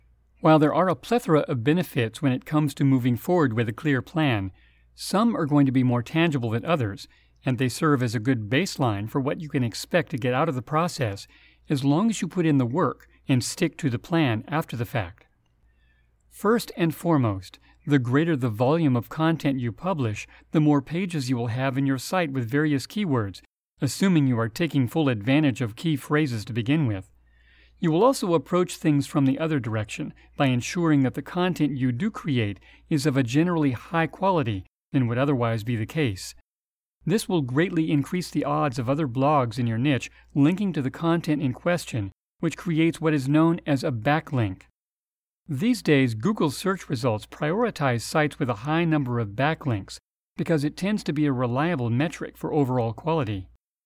Mature Adult, Adult
Has Own Studio
My voice can be described as deep, smooth, fatherly, and kind, with an authoritative, story-teller vocal style.